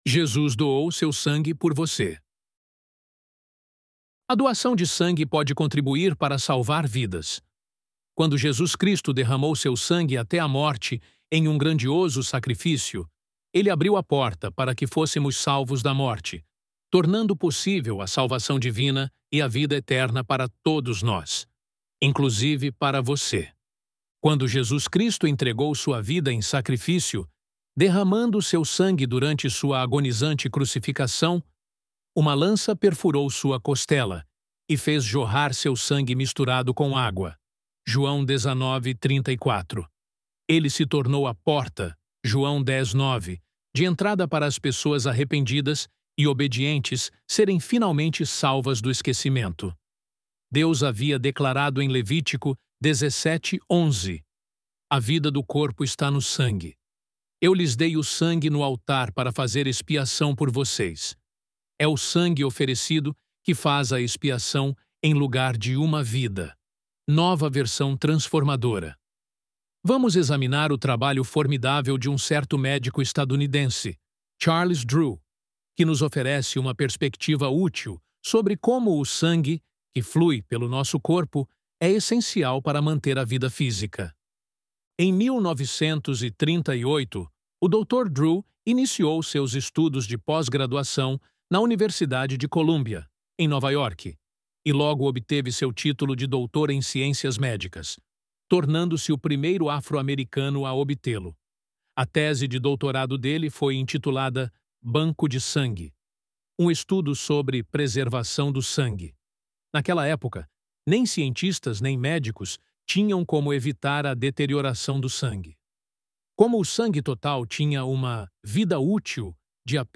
ElevenLabs_Jesus_Doou_Seu_Sangue_Por_Você.mp3